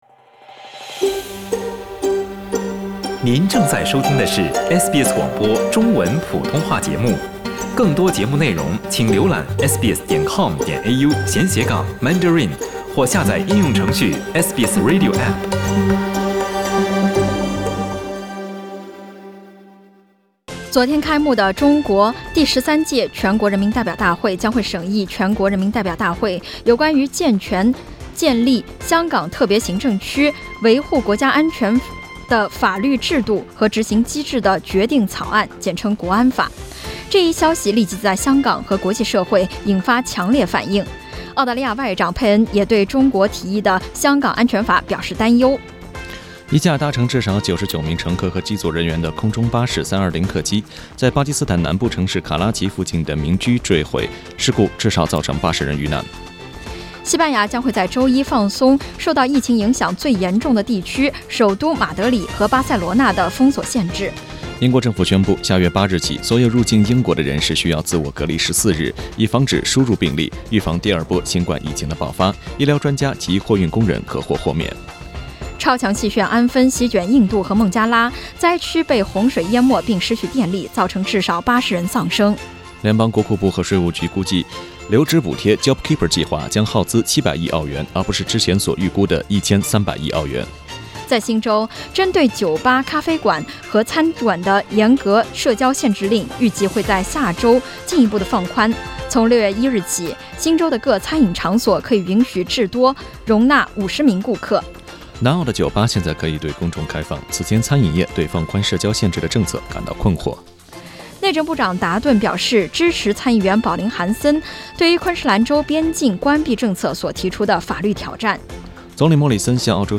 SBS早新闻（5月23日）